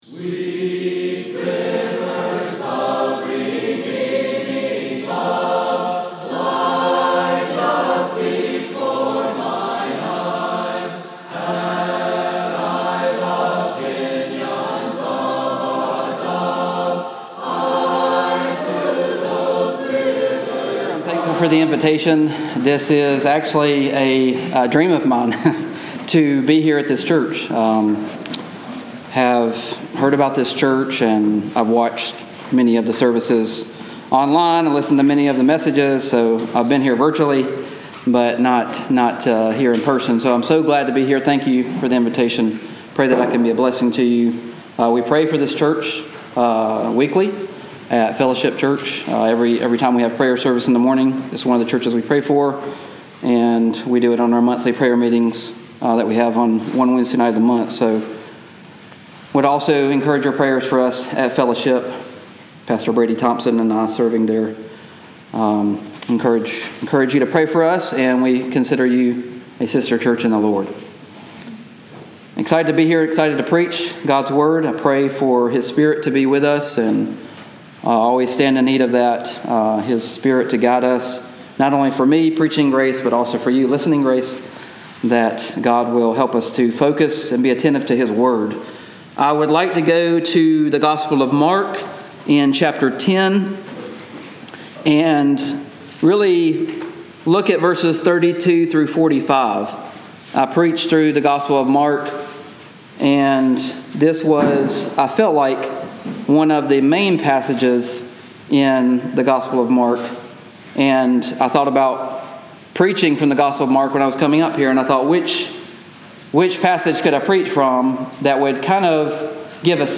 Sermoncast – He came to Suffer, Sacrifice, Serve, Save – Mt Carmel Primitive Baptist Church